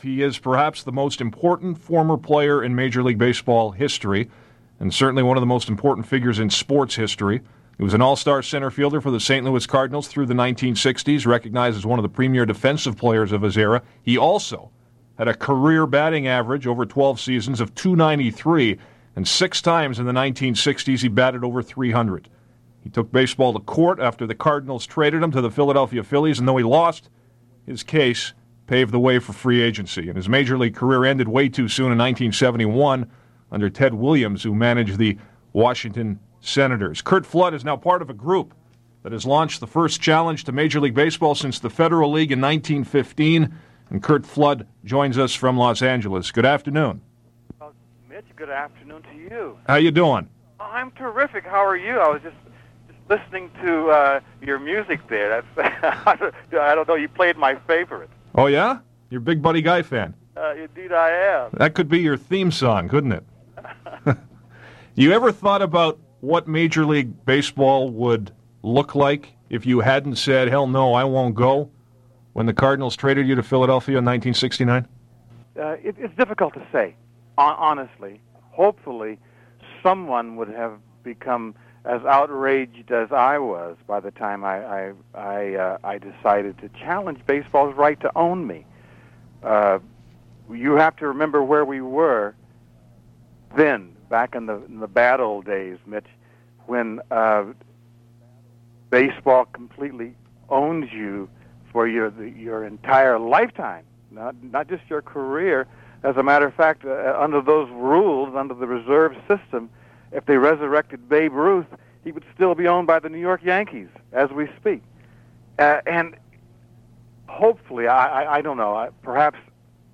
Overall, it’s a fascinating conversation about a significant moment in baseball history and a look back at Flood’s beginnings in pro ball as a young man from Oakland breaking into the sport during the Jim Crow era in the Deep South.